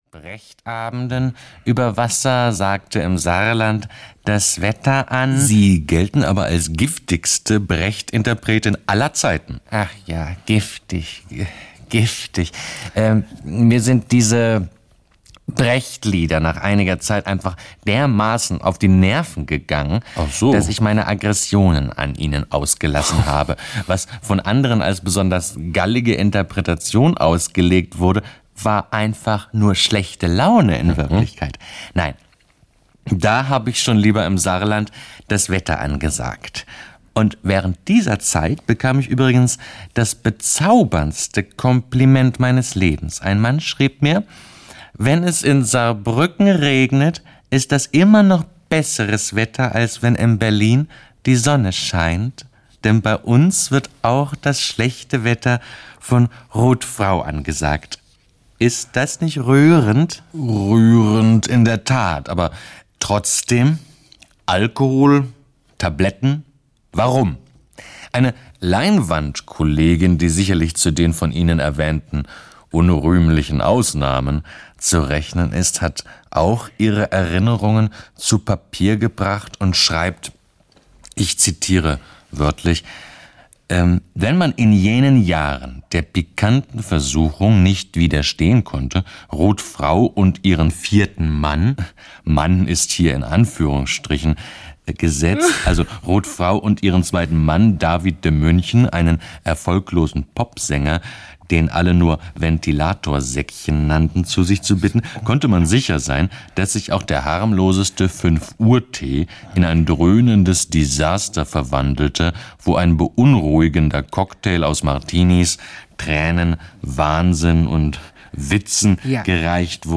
Ein Leben auf der Flucht vor der Koralle - Max Goldt - Hörbuch